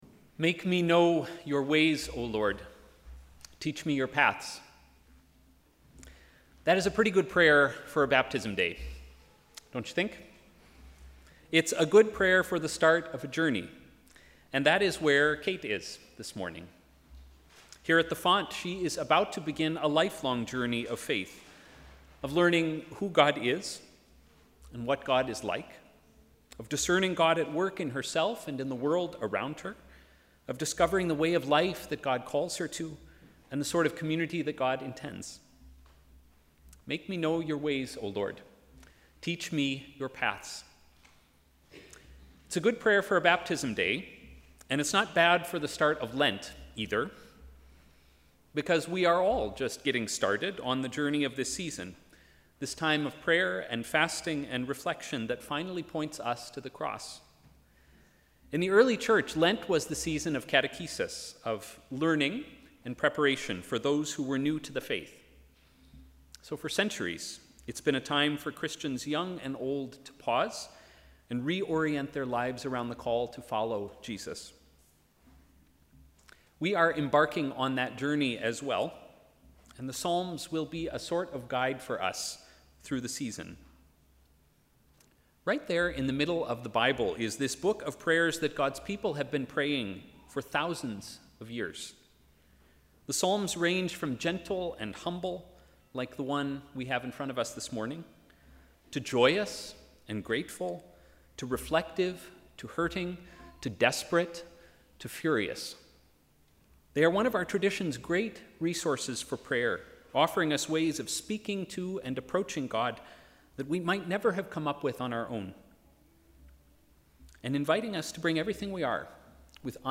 Sermon: ‘Teach me your paths’